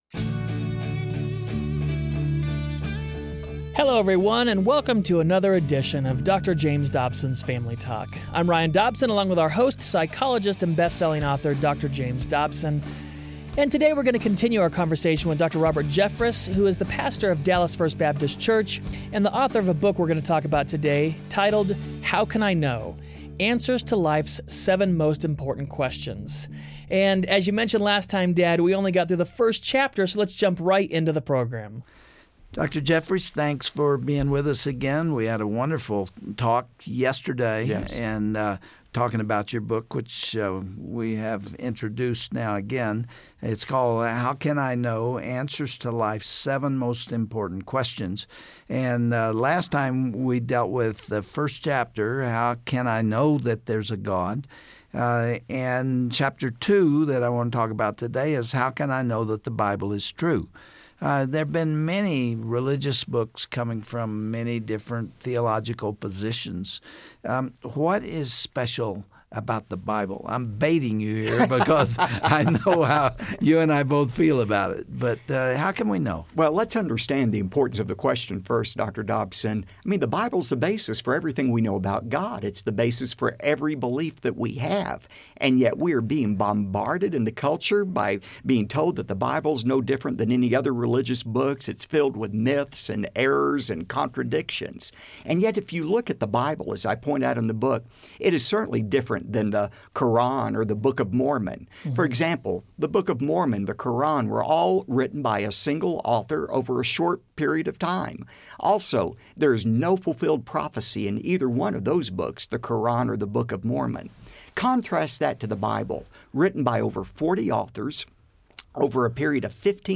So what are the most important questions a person can ask? On the next edition of Family Talk, Dr. James Dobson will interview Dr. Robert Jeffress about lifes most important questions.